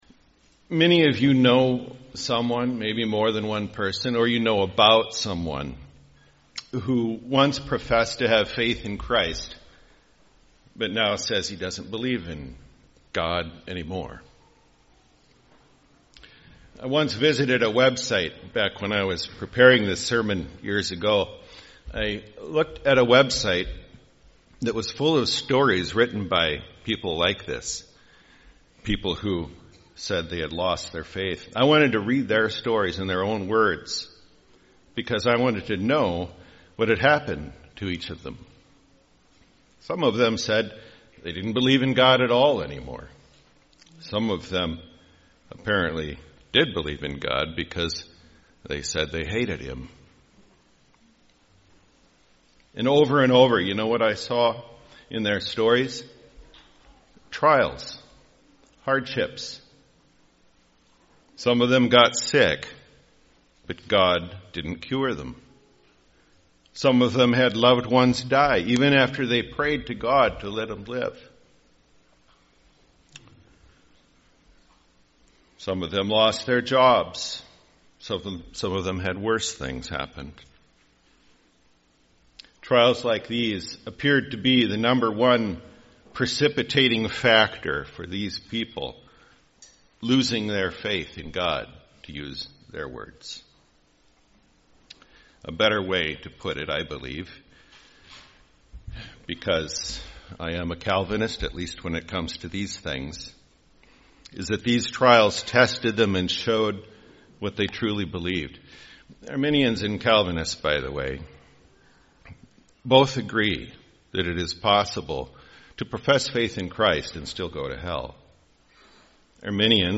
» Sermon Audio